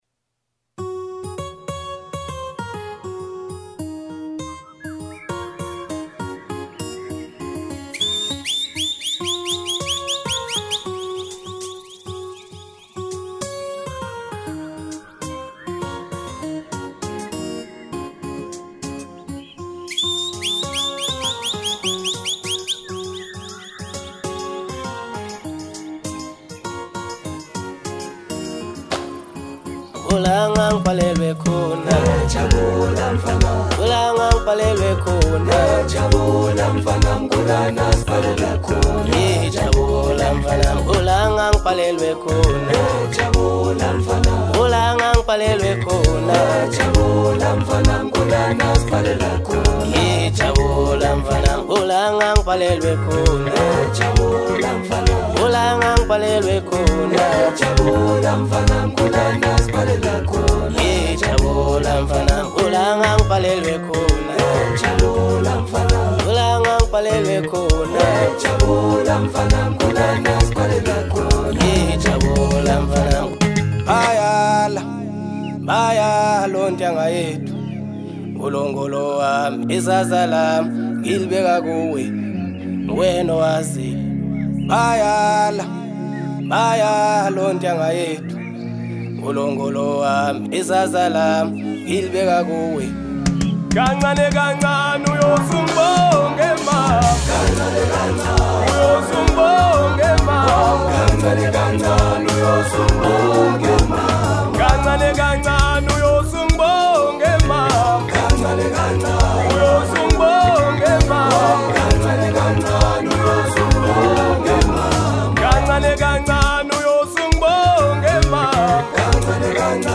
Genre : Maskandi